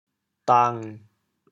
“峒”字用潮州话怎么说？
dang7.mp3